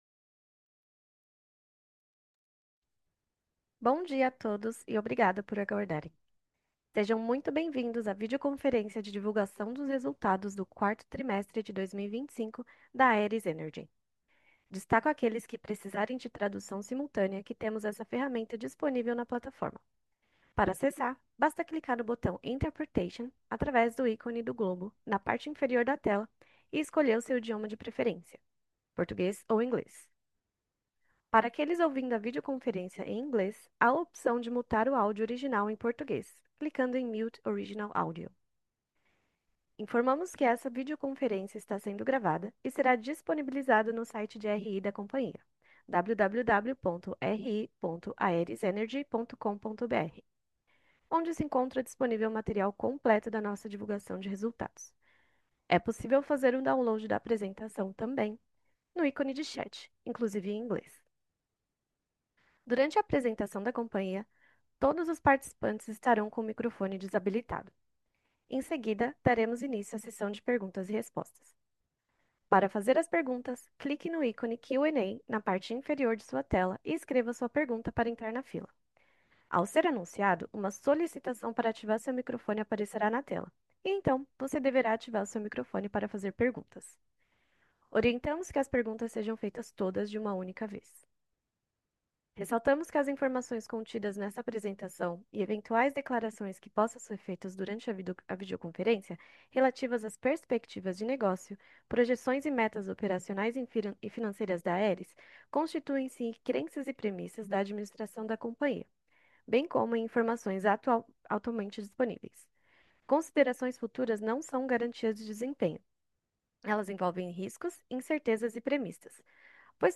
teleconferencia_4t25.mp3